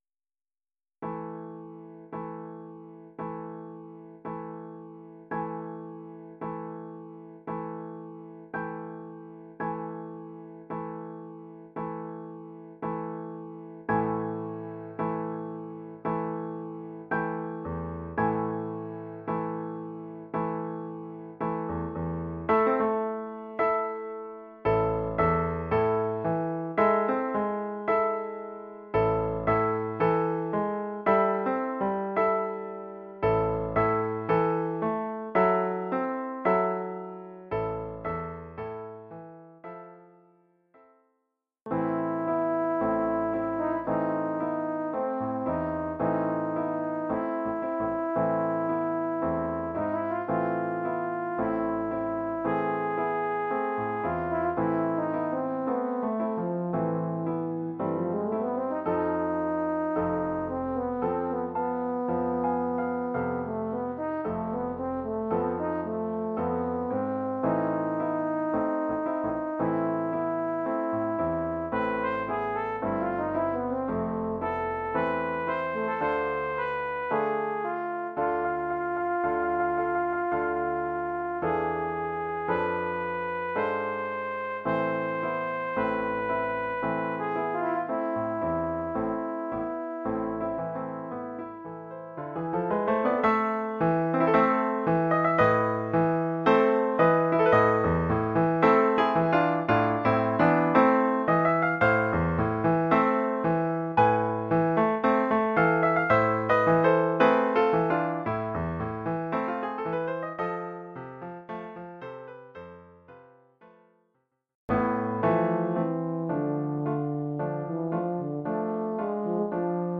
Oeuvre pour saxhorn basse / euphonium / tuba et piano.